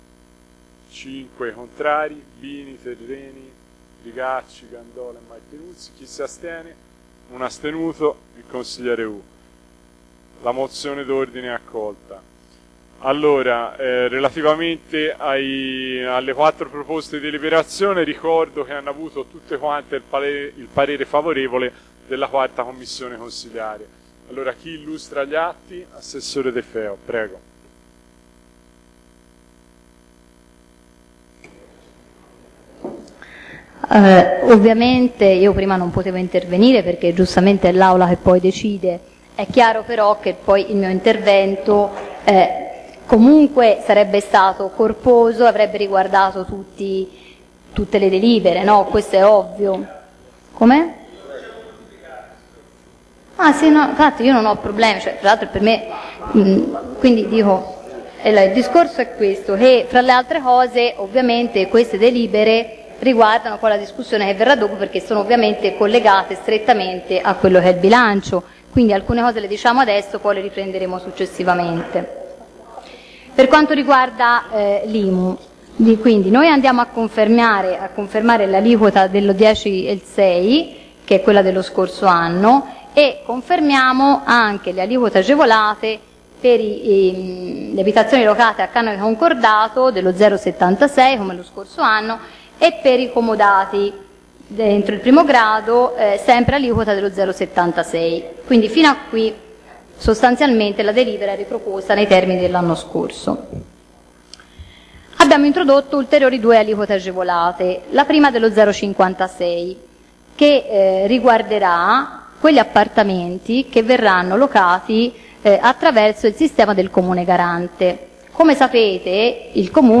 2015-07-30_consiglio-comunale-de-feo-tasse.mp3